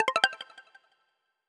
OpenOrEnable4b.wav